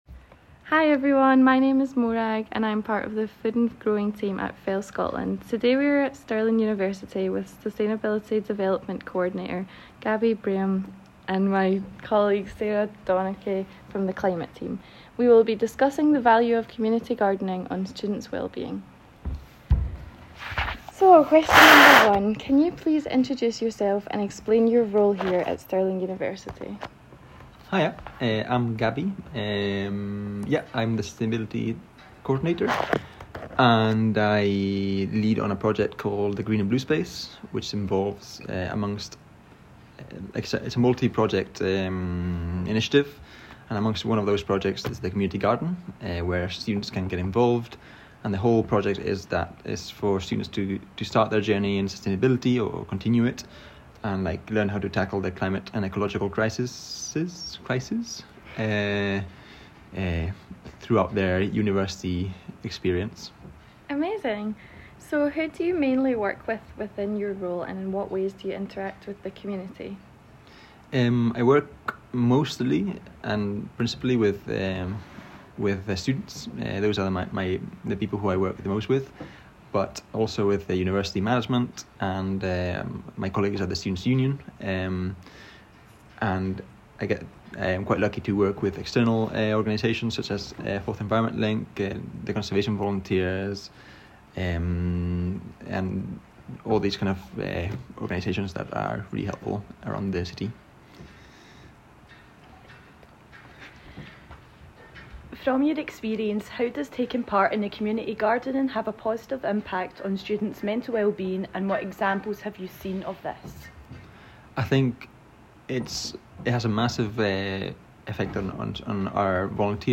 Community Garden Interview: The Value of Gardening on Students’ Wellbeing